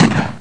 FIRE3.mp3